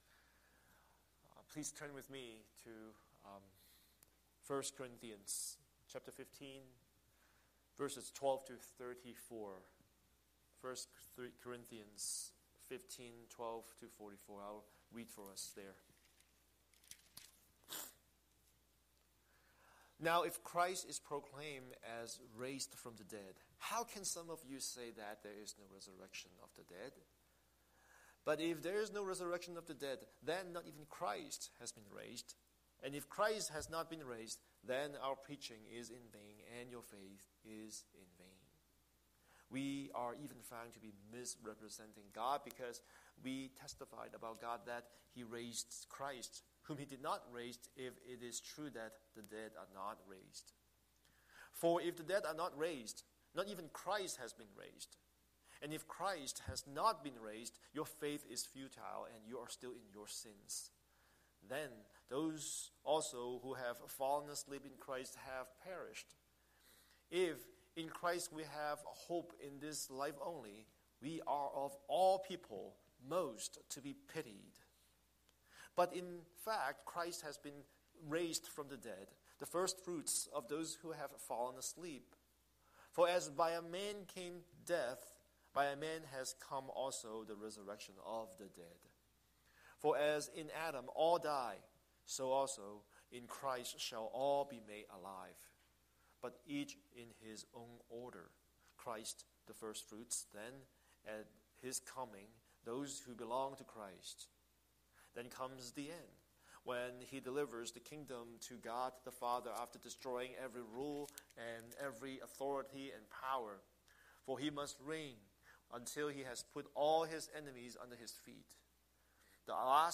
Scripture: 1 Corinthians 15:12–34 Series: Sunday Sermon